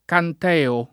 canteo [ kant $ o ]